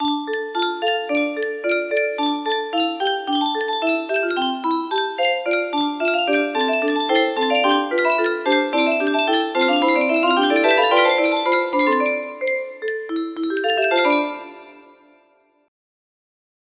Compositie #1 music box melody
Wow! It seems like this melody can be played offline on a 15 note paper strip music box!